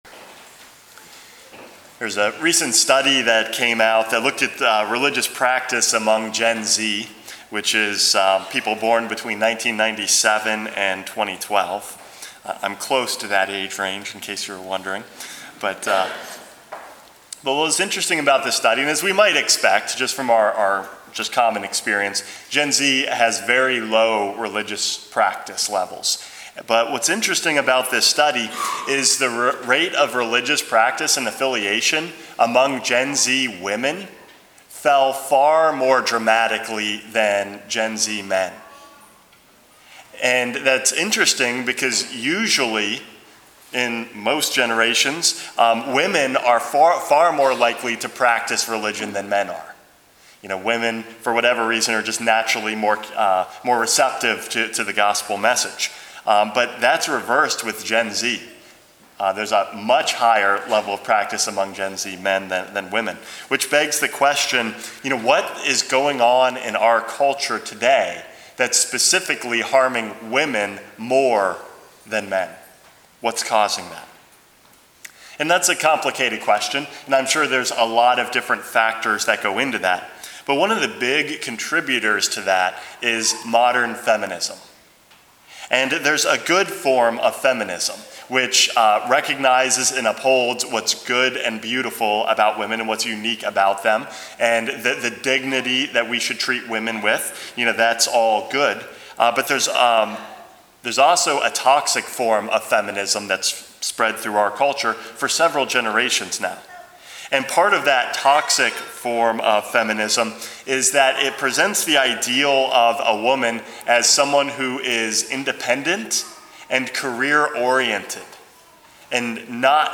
Homily #417 - True Feminism